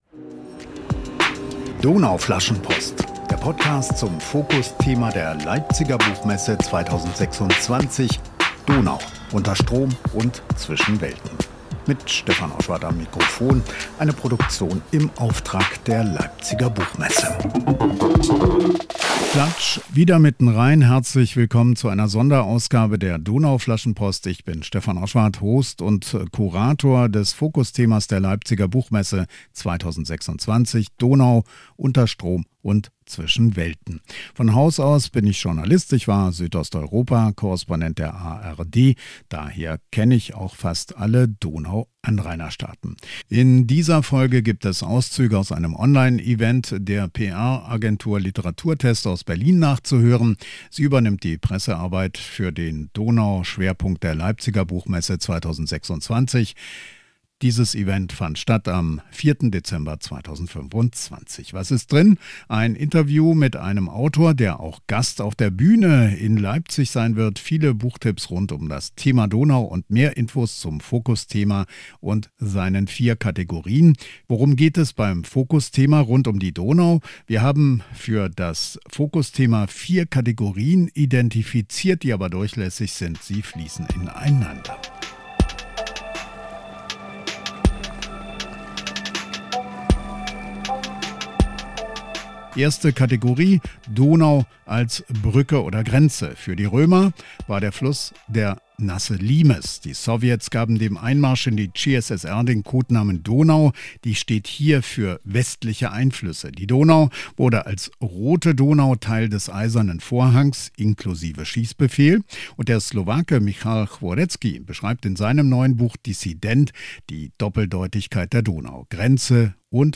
Die Berliner Agentur "Literaturtest" hat dazu am 4.12.2025 ein Online-Event veranstaltet.